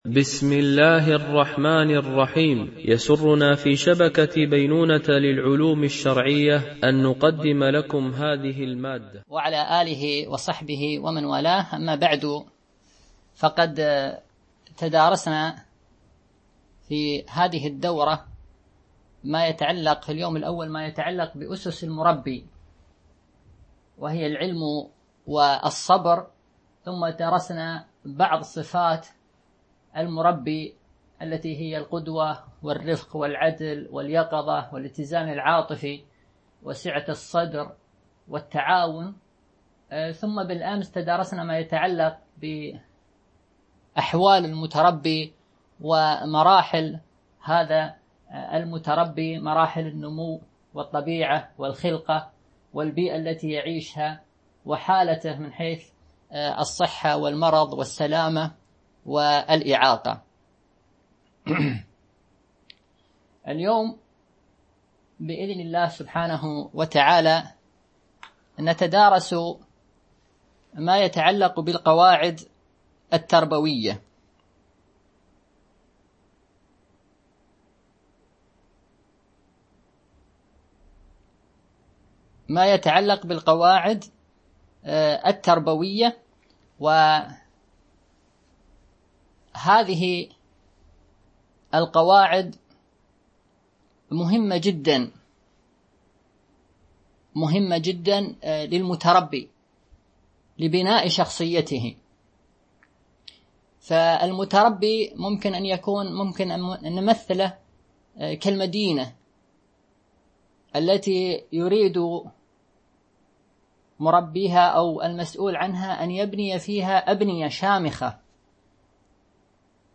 سلسلة محاضرات قواعد تربوية وتحديات عصرية